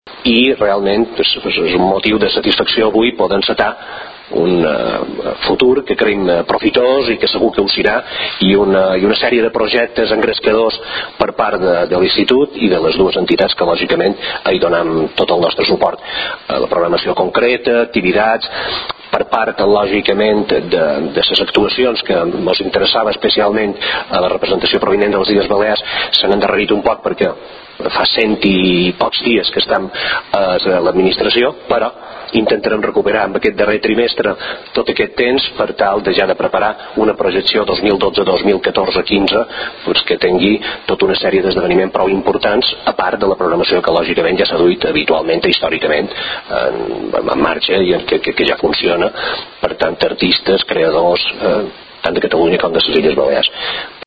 Àudio: el conseller d'Educació, Cultura i Universitats del Govern de les Illes Balears , Rafael Bosch, valora la primera reunió del Consell de Direcció de l'IRL